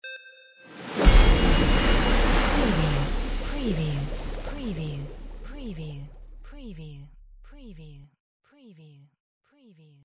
SCIFI_MAGIC_WATER_RUNE_WBSD05B
Stereo sound effect - Wav.16 bit/44.1 KHz and Mp3 128 Kbps
previewSCIFI_MAGIC_WATER_RUNE_WBHD05B.mp3